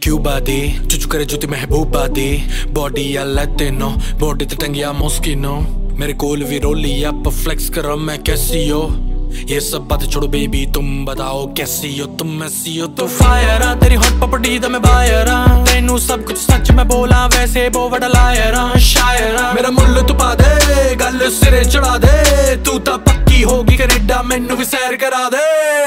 Bollywood Ringtones